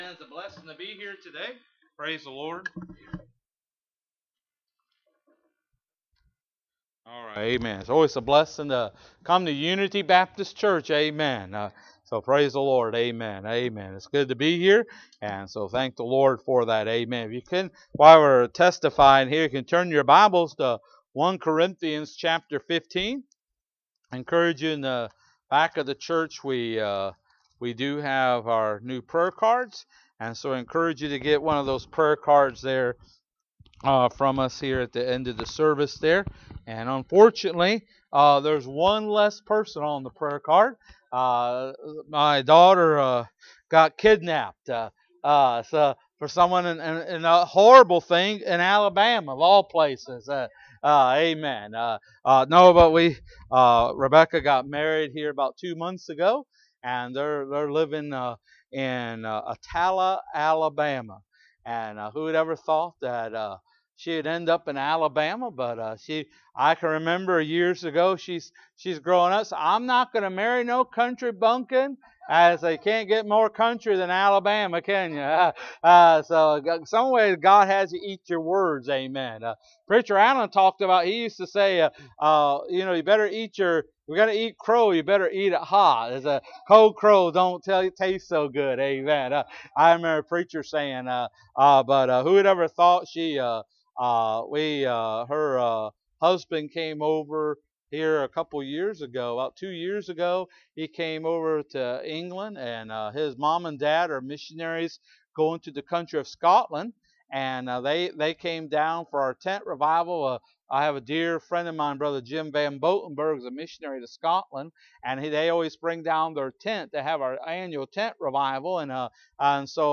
1 Corinthians 15:1-4 Service Type: Sunday Morning Bible Text